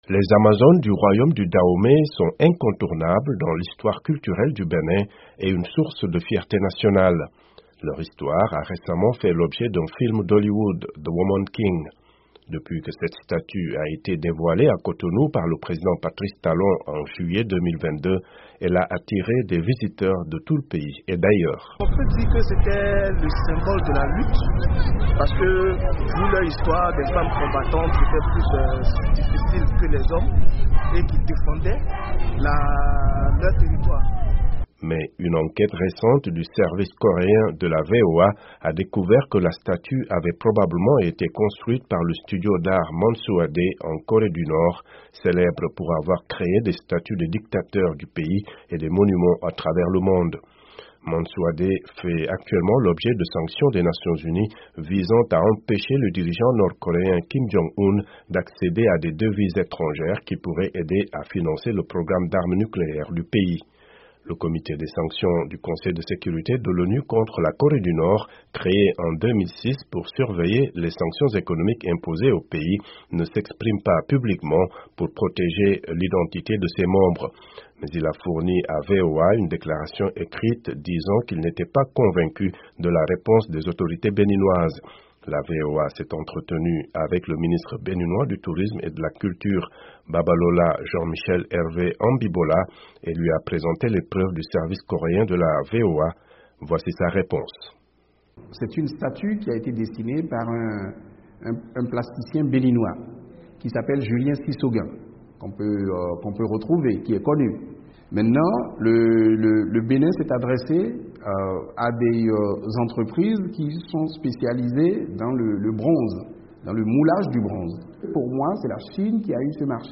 Une statue au Bénin de l'une des femmes guerrières du Dahomey, qui est apparue dans le film hollywoodien "The Woman King", a probablement été construite par une société nord-coréenne sous sanction, selon une enquête du service coréen de VOA. Dans une interview exclusive avec la Voix de l’Amérique, le gouvernement béninois dément l’implication de la Corée du Nord dans l’édification de cette statue. Un reportage